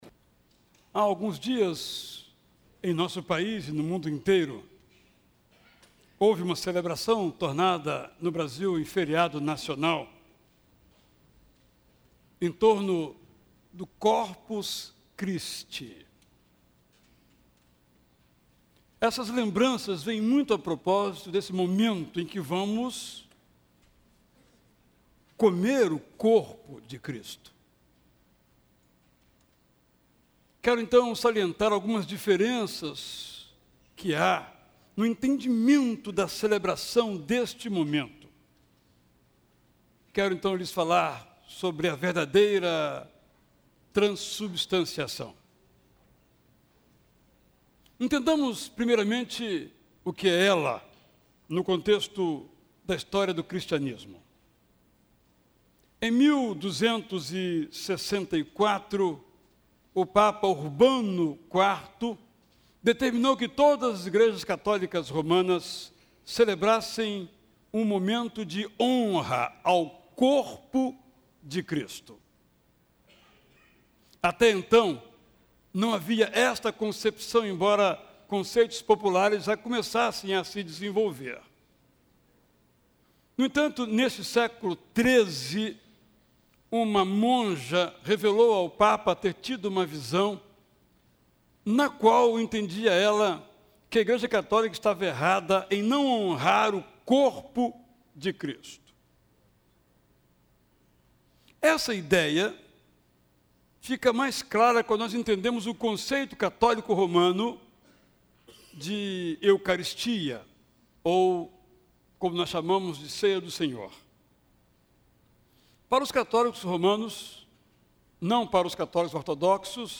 MENSAGEM EM ÁUDIO: TRANSUBSTANCIAÇÃO